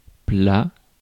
Plats (prononcé [pla]